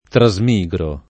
trasmigro [ tra @ m &g ro ]